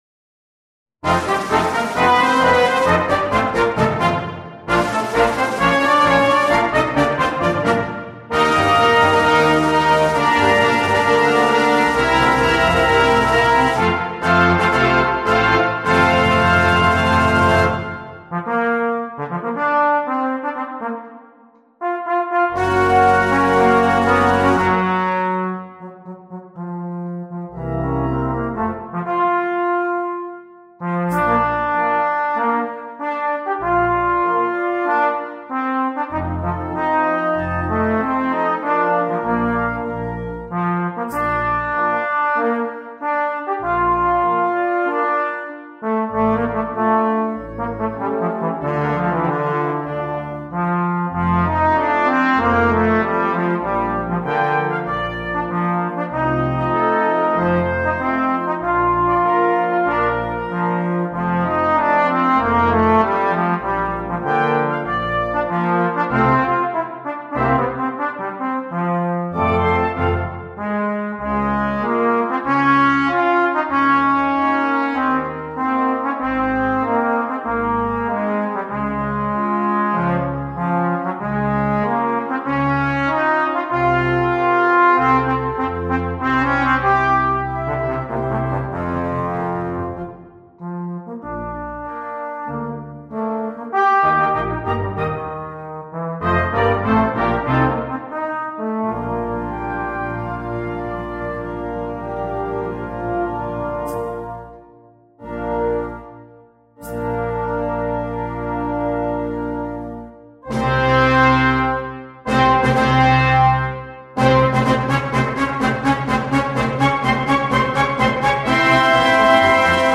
Full Band
with solo instrument
Tenor Trombone (Solo), Tenor Trombone C – Bass clef (Solo)
Classical
Music Sample